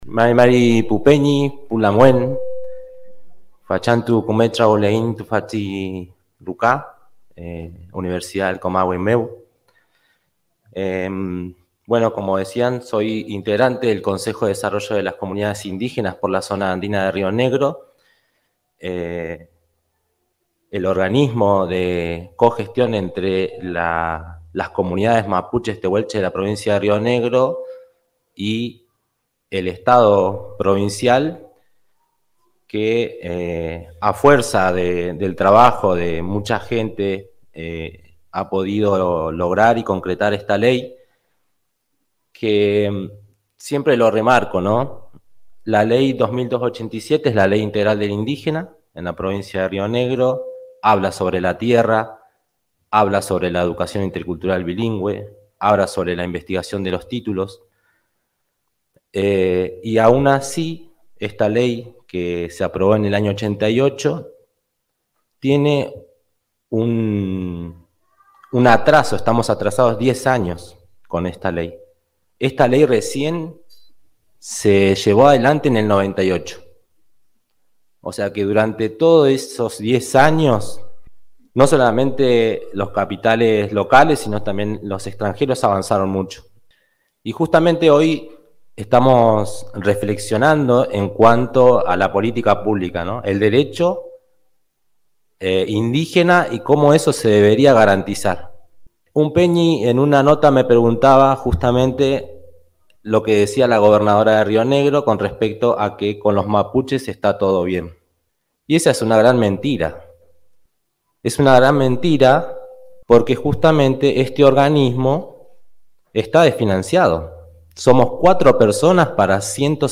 Exposición